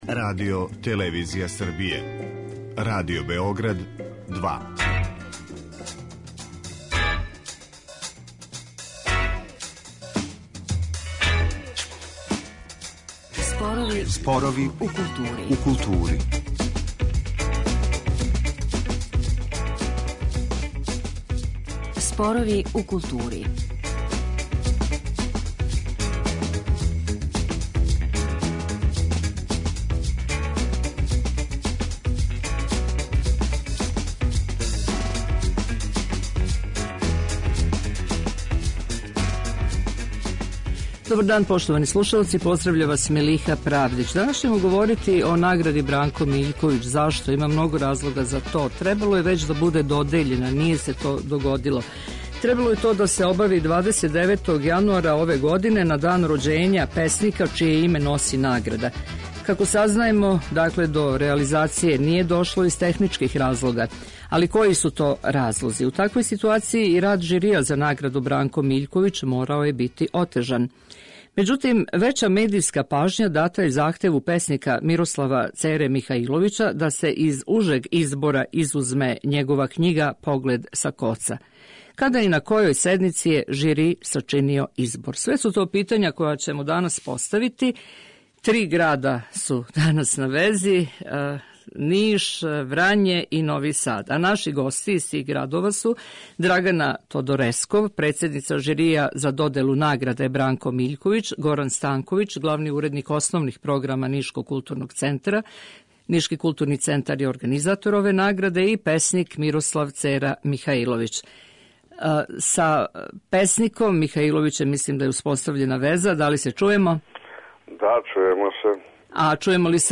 О свим перипетијама које прате овогодишњу награду "Бранко Миљковић", разговарамо у данашњој емисији и питамо се зашто се све ово догађа када Награду треба доделити 46. пут.